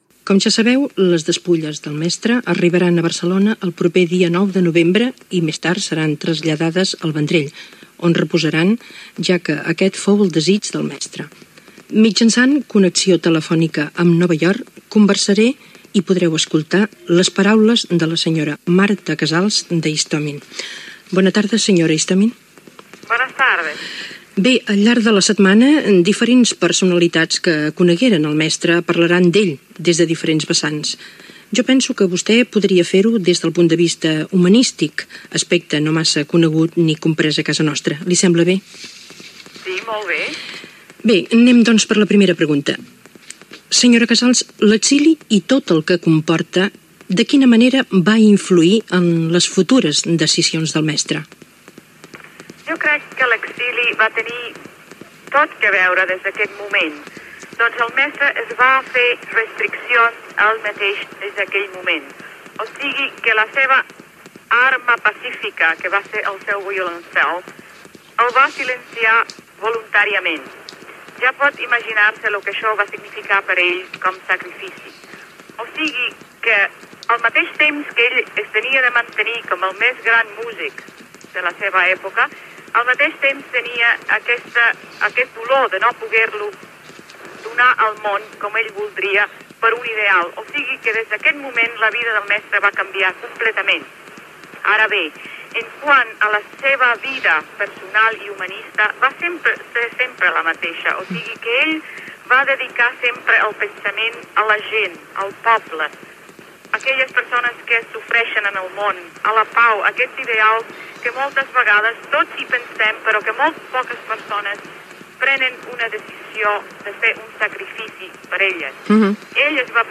Informació de l'arribada de les despulles del músic Pau Casals a Catalunya amb una entrevista telefònica a la seva vídua Marta Casals Istomin
Info-entreteniment